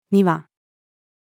庭-female.mp3